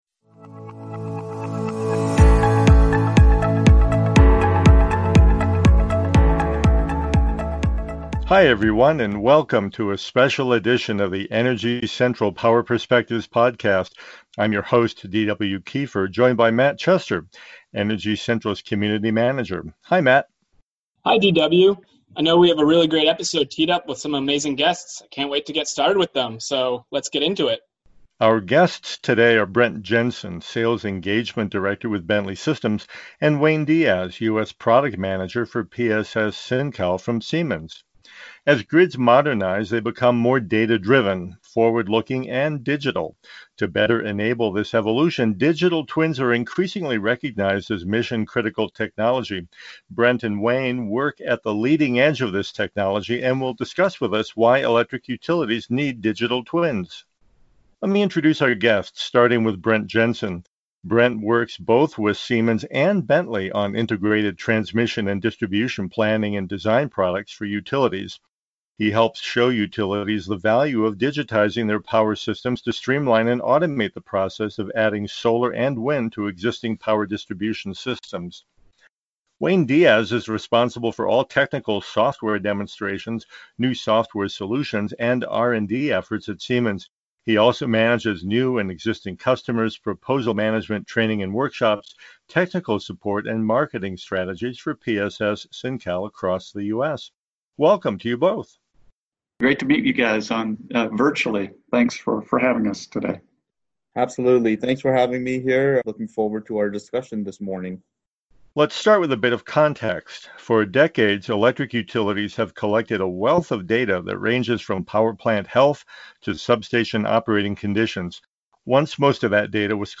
This week, Energy Central brings you a special conversation about the digitalization of the utility sector and the imperative role that digital twin technology will play in this industry evolution.